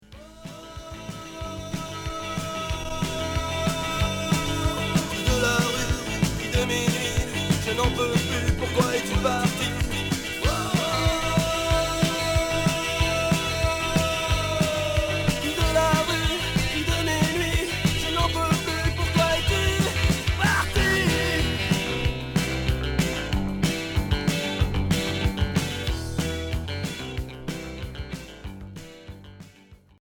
Rock Cold wave Unique 45t retour à l'accueil